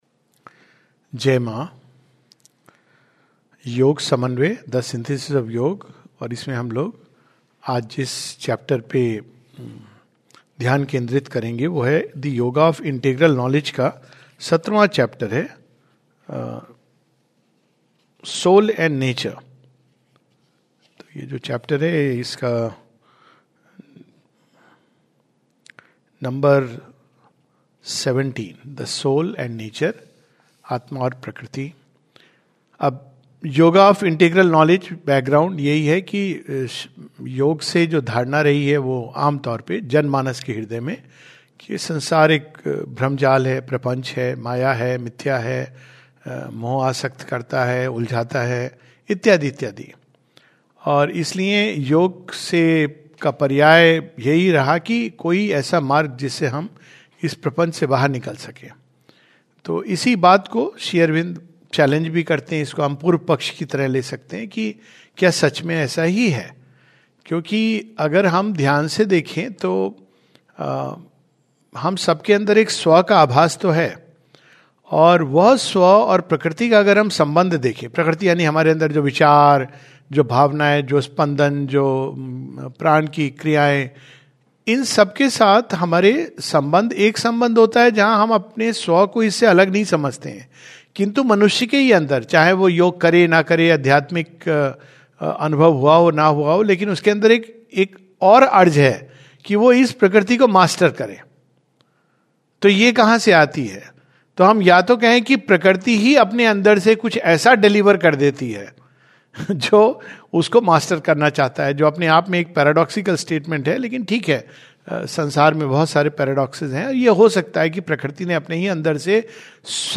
This is a summary of Chapter 17 of the Yoga of Integral Knowledge. A talk